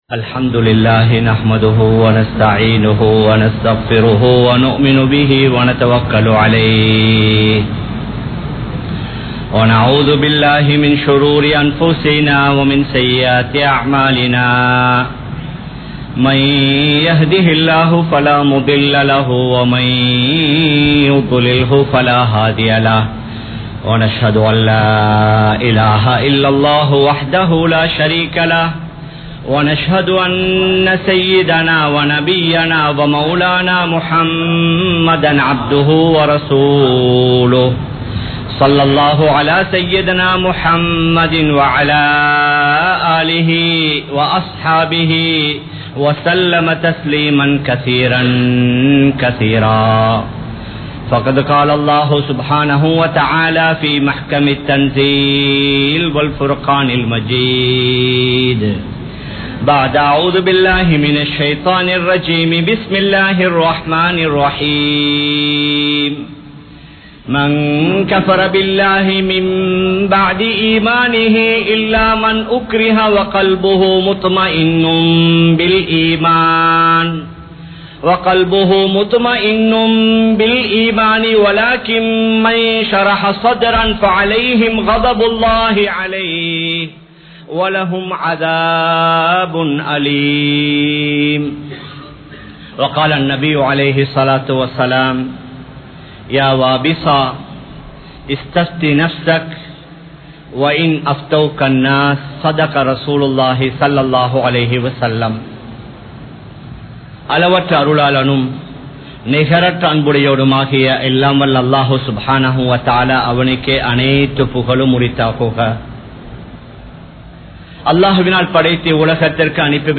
Fathwa & Thaqwa (பத்வா & தக்வா) | Audio Bayans | All Ceylon Muslim Youth Community | Addalaichenai
Colombo 03, Kollupitty Jumua Masjith